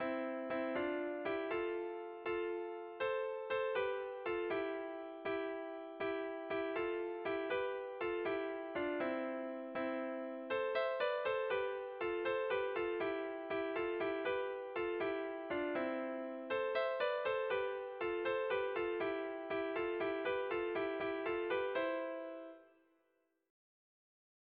Gabonetakoa
Neurrian ez dabil zuzen zuzen, 10/9 koak baitira hirutik bi puntu.
Zortziko handia (hg) / Lau puntuko handia (ip)
A-A-B-B2